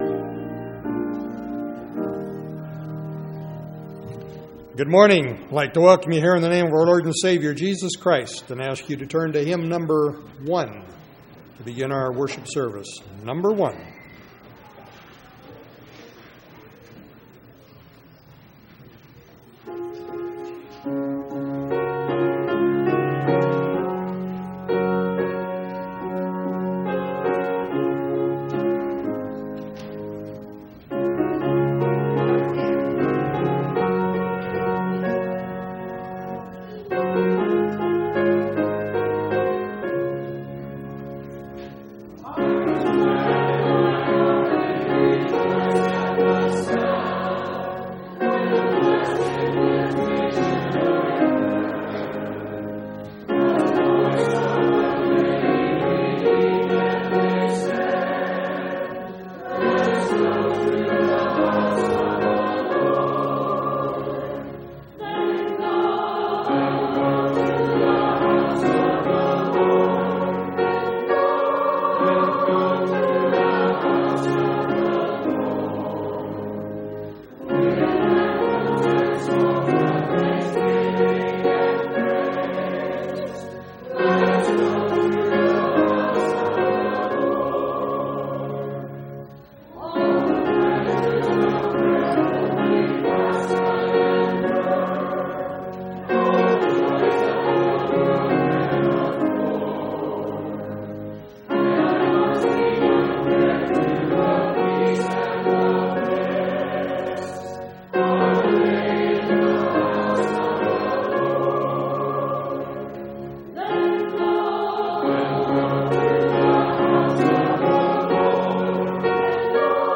7/29/2005 Location: Missouri Reunion Event: Missouri Reunion